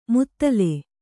♪ muttale